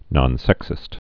(nŏn-sĕksĭst)